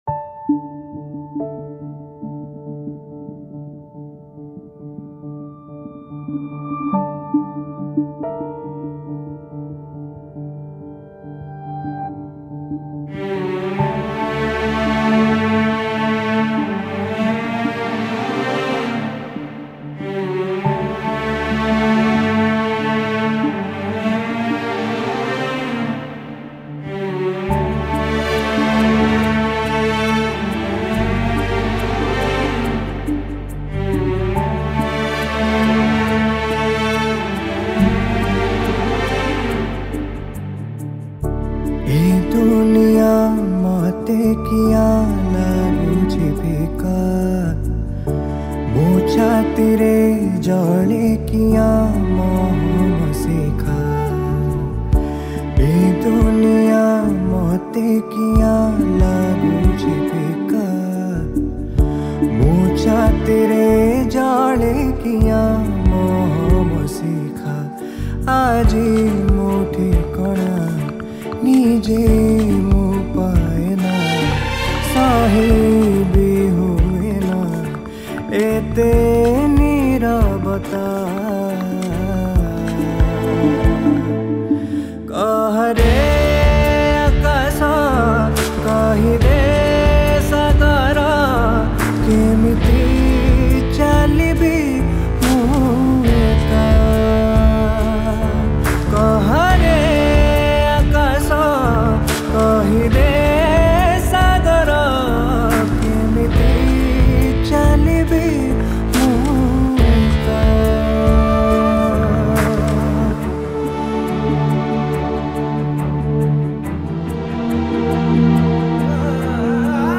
Guitars
Bass
Flute